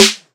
BWB THE WAVE SNARE  (36).wav